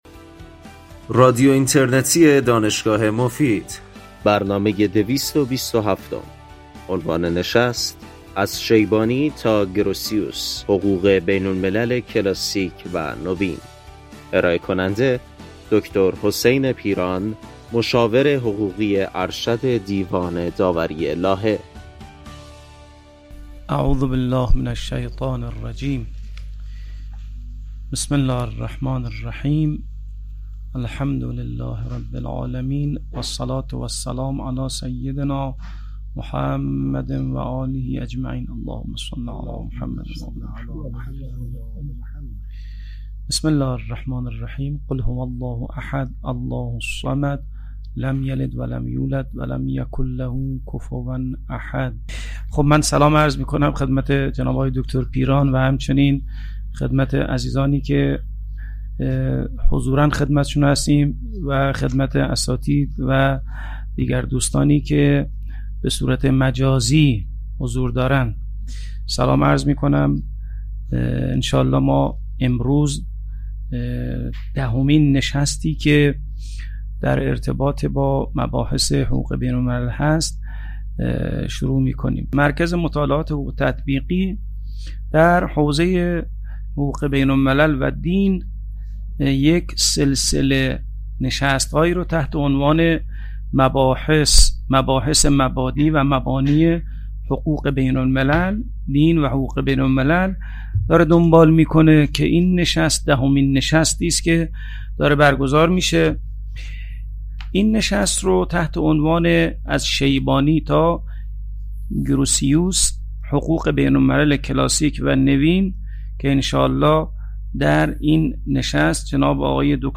این سخنرانی در سال ۱۴۰۲ ایراد شده است.
بخش پایانی برنامه به پرسش و پاسخ اختصاص دارد.